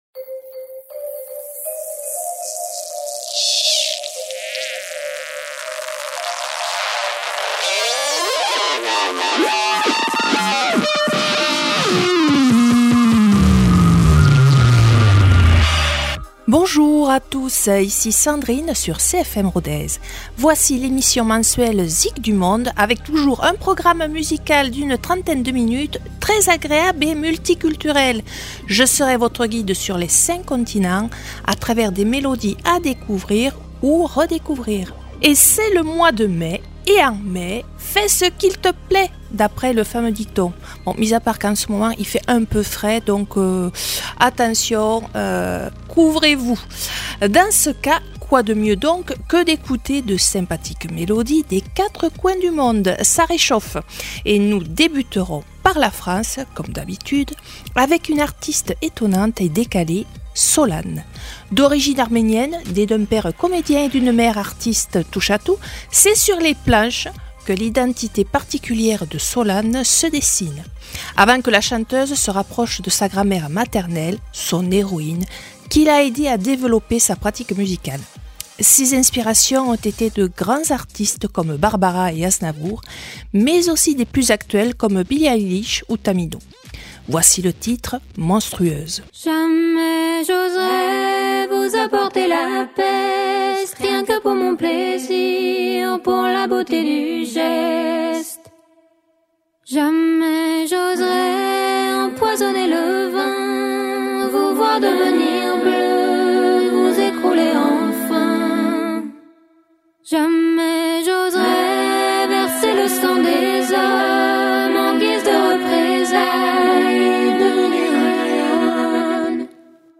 La balade musicale et printanière sur les cinq continents, c’est maintenant.